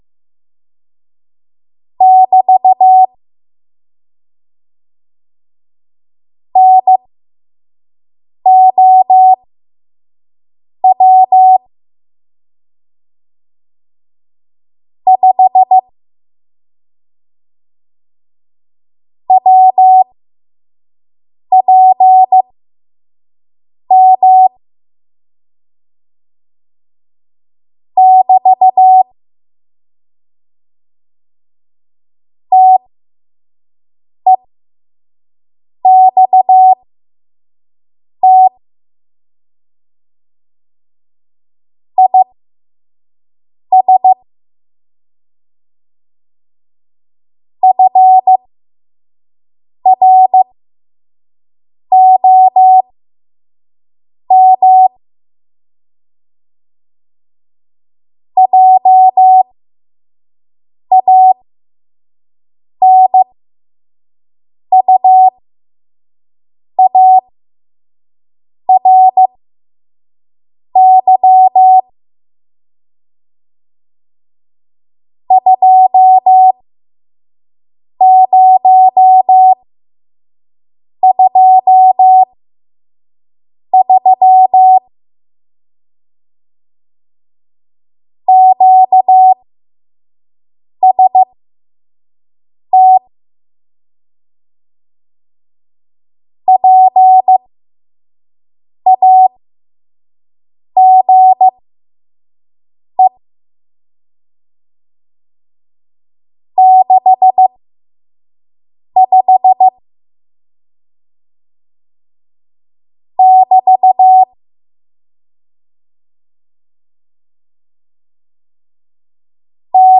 5 WPM Code Archive